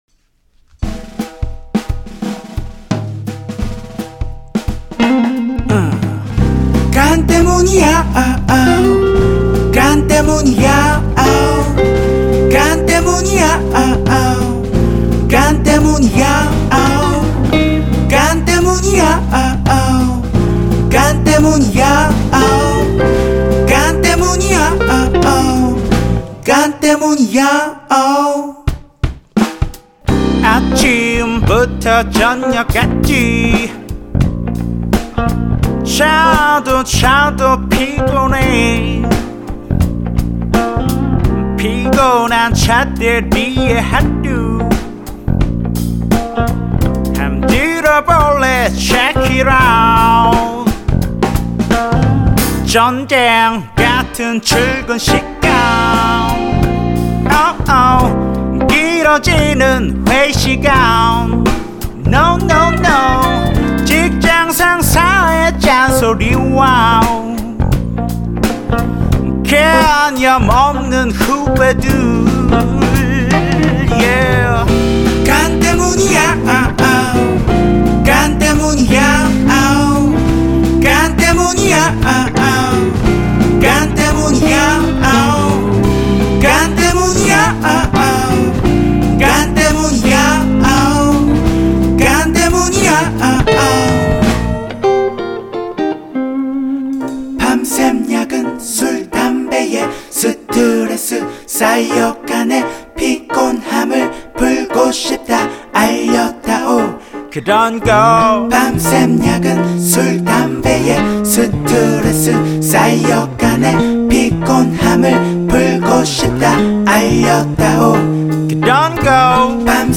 Vocal
Drum
Bass
Piano
Guitar
믹싱을 다시 해보았습니다.